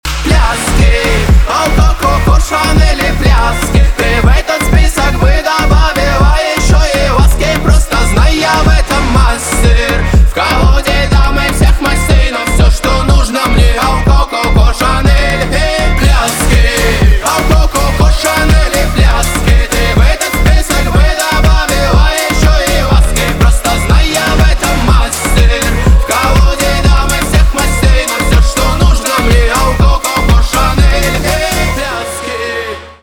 поп
танцевальные
басы